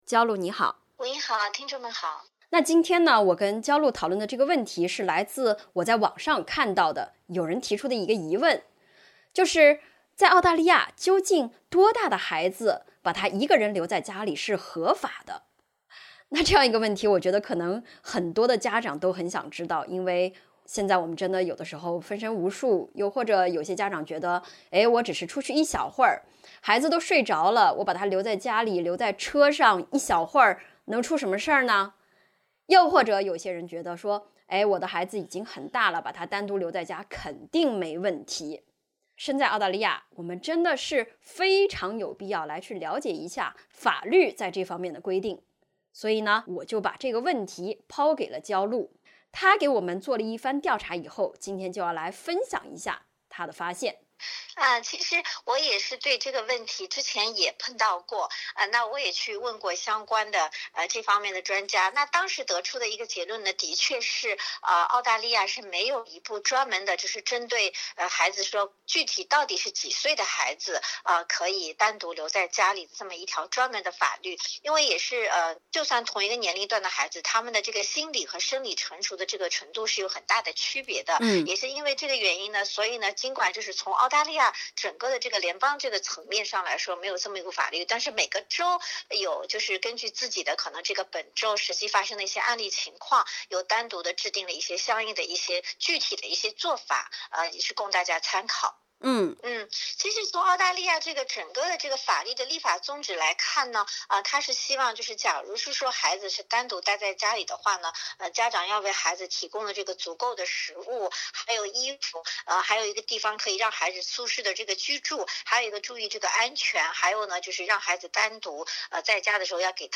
孩子多大可以独自在家？澳洲法律这样规定（点击封面图片收听完整采访）。